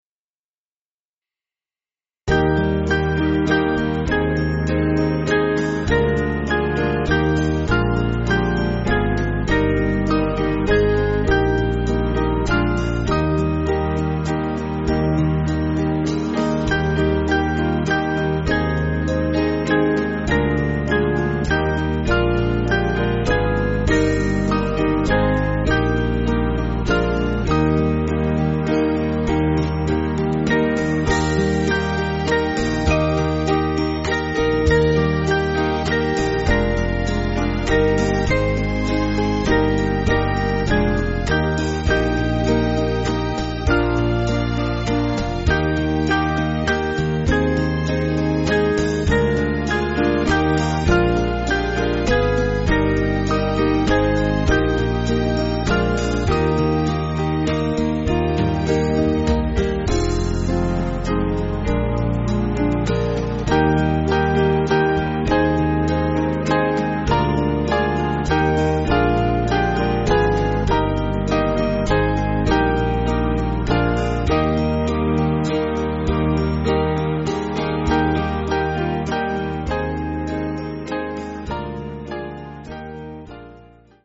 Small Band
(CM)   5/Eb